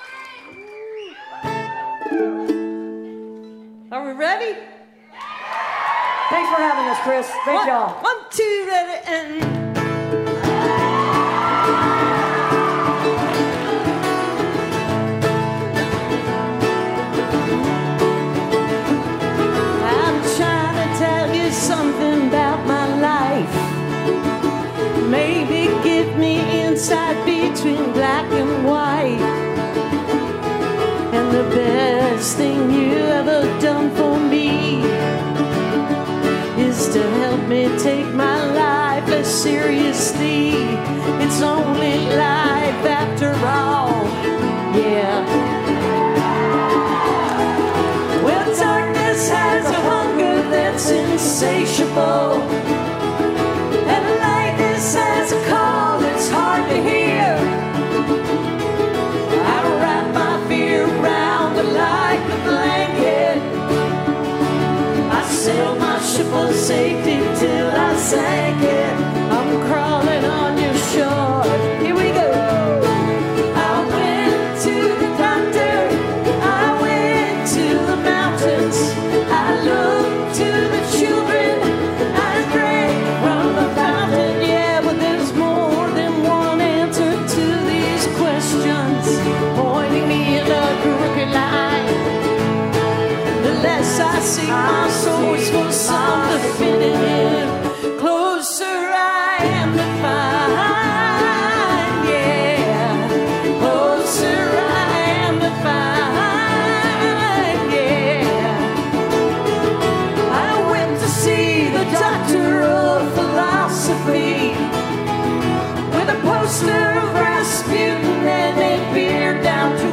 (captured from a youtube live stream)